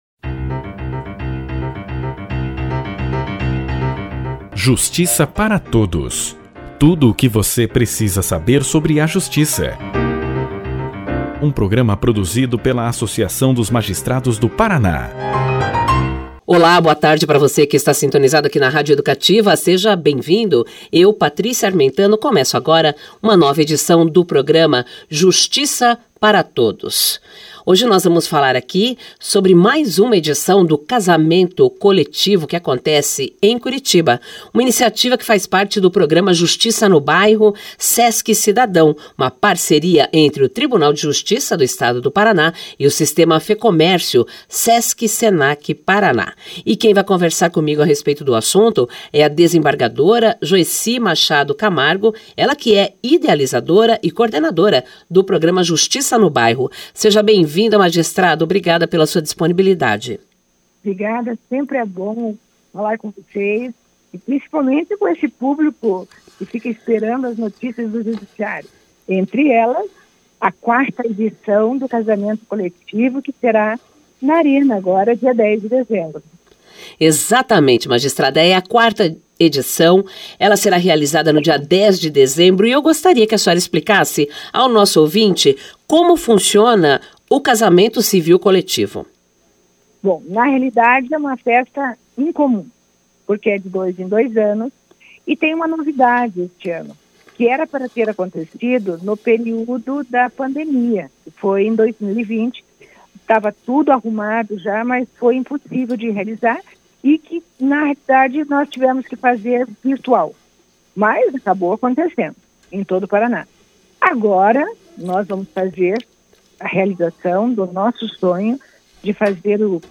>>Clique Aqui e Confira a Entrevista na Integra<<
O Programa de rádio da AMAPAR, Justiça Para Todos, entrevistou a idealizadora e coordenadora do Programa Justiça no Bairro, Desembargadora Joeci Machado Camargo. Na conversa, a magistrada falou da organização do casamento civil coletivo que acontece dia 10 de dezembro, na Arena da Baixada, em Curitiba.